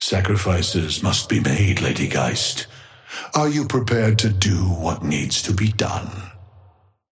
Amber Hand voice line - Sacrifices must be made, Lady Geist.
Patron_male_ally_ghost_oathkeeper_5g_start_01.mp3